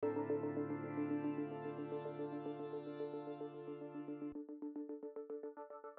Орган, лееринг
Я имею в виду пока без плака, который звучит сверху. Питч модуляция не на всех нотах аккорда, фильтр тоже открывается на одной ноте вроде.